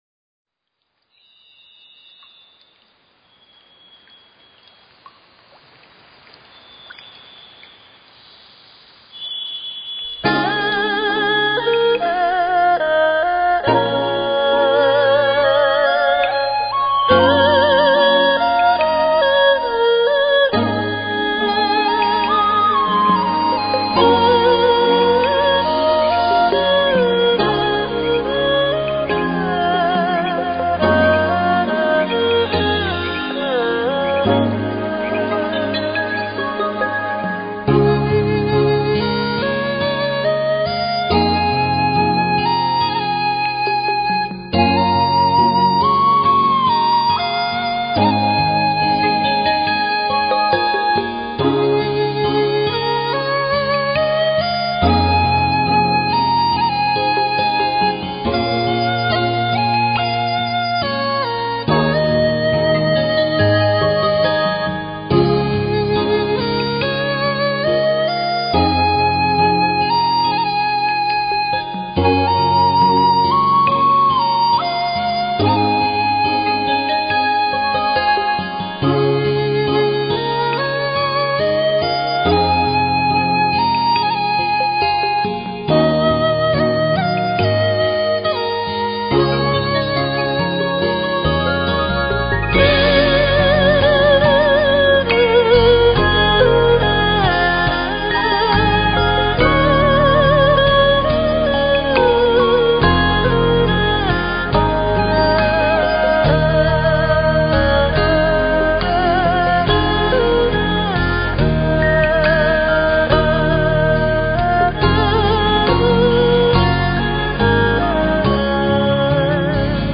耳边，一曲《山野幽居》清澈而自然的回荡着；